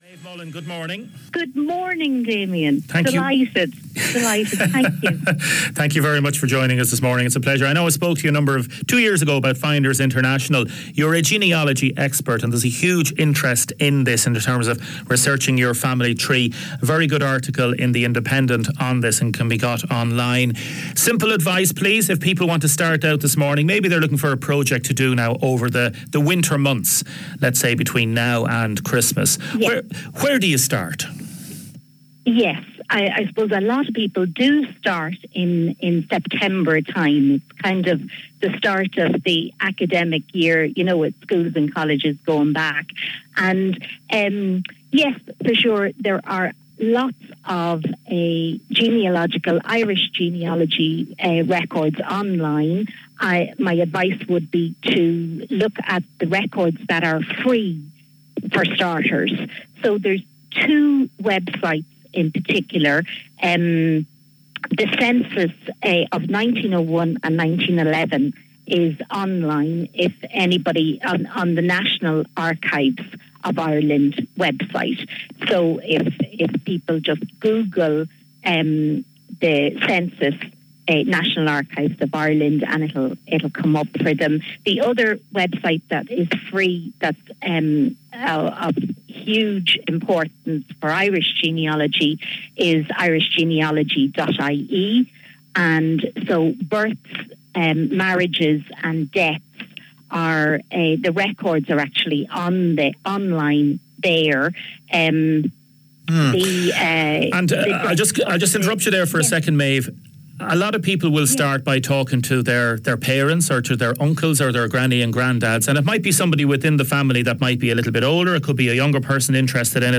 Later they discuss some of the more complicated cases that Finders International has had. Listen to the interview below.